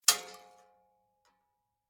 shotgun_metal_5.ogg